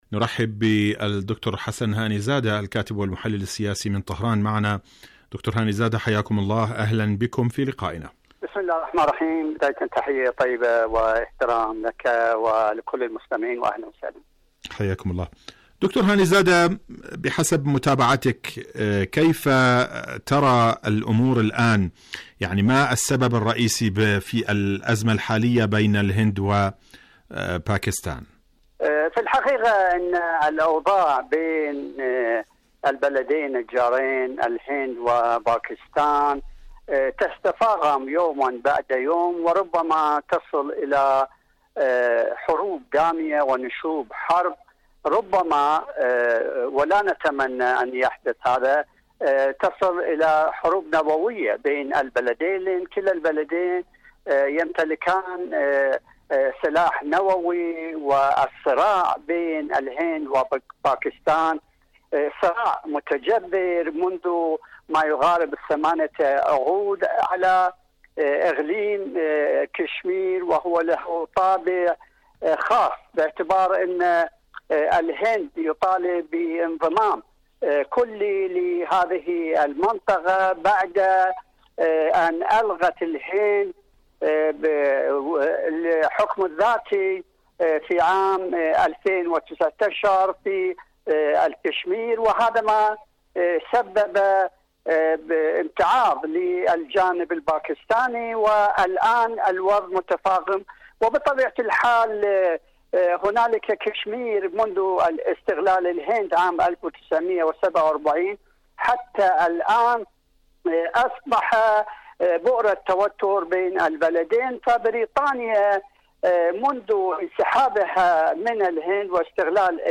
برنامج حدث وحوار مقابلات إذاعية إيران والمساعي الحميدة بين الهند والباكستان